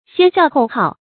先笑后号 xiān xiào hòu hào
先笑后号发音
成语注音 ㄒㄧㄢ ㄒㄧㄠˋ ㄏㄡˋ ㄏㄠˋ